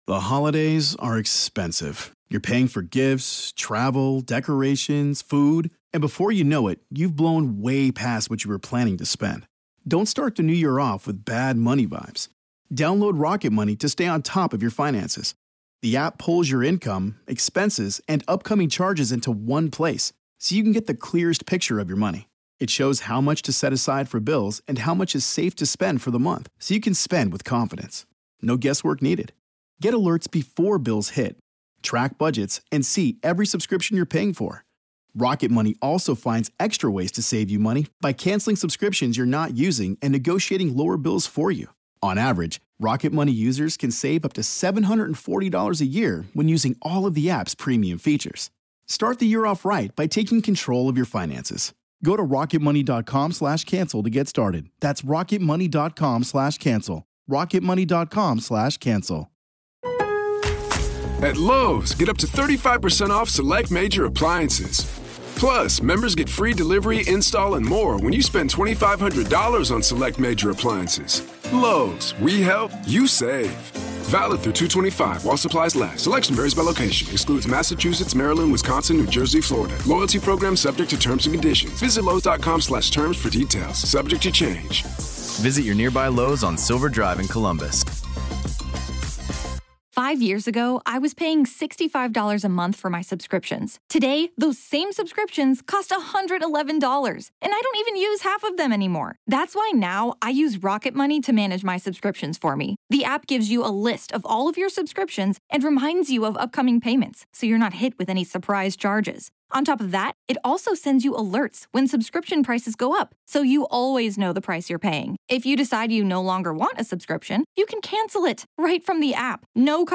Today on the Grave Talks, Part One of our conversation